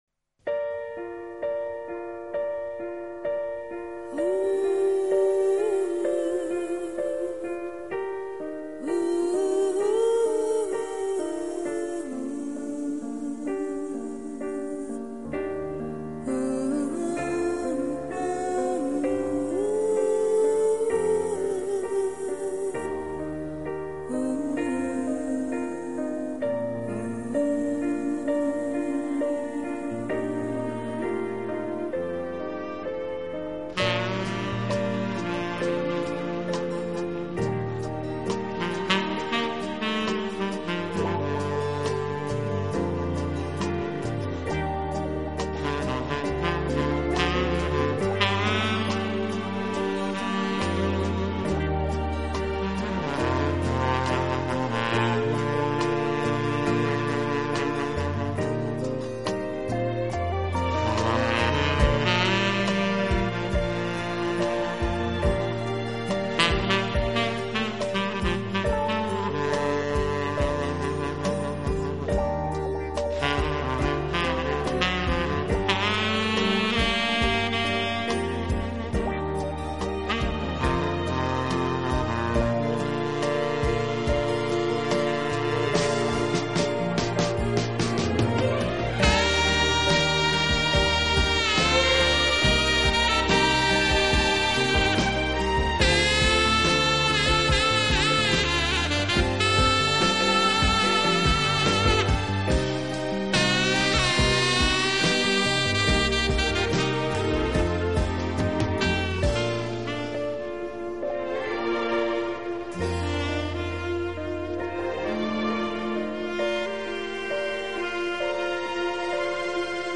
一直以来的灼热萨克斯演奏风格。